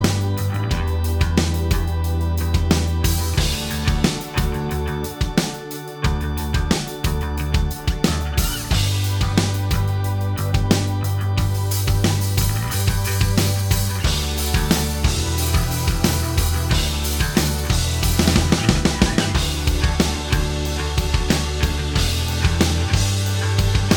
Minus All Guitars Pop (2000s) 4:53 Buy £1.50